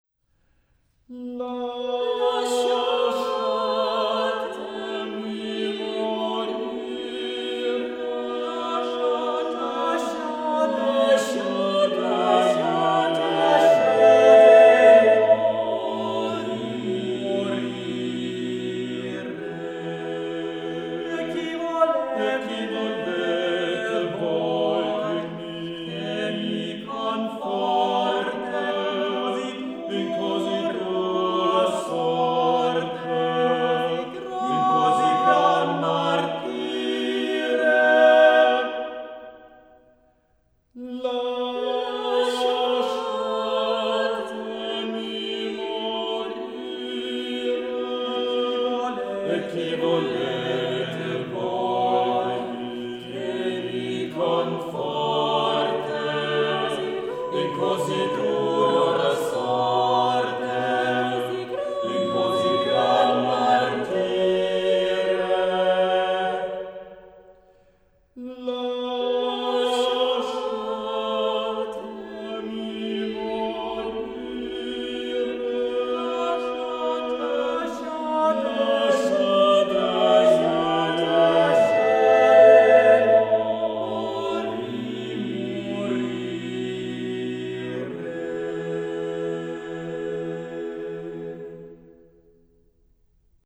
sopran
alt
tenor